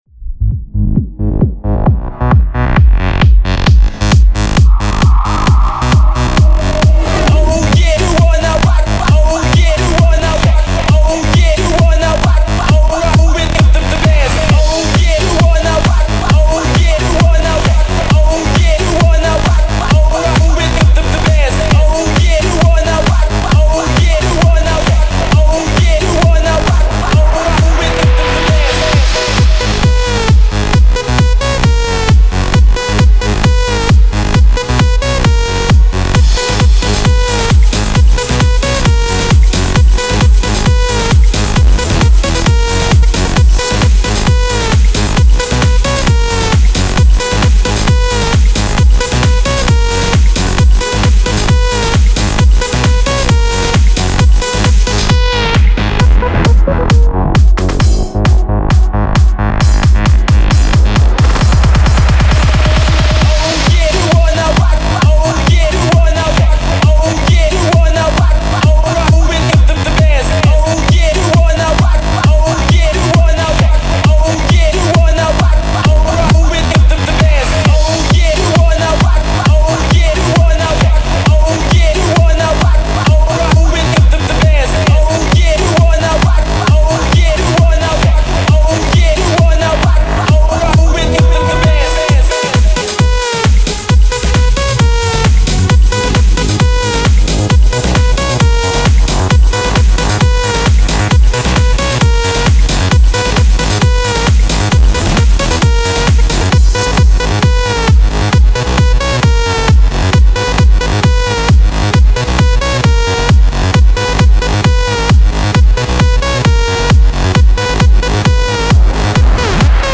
Жанр: Electro House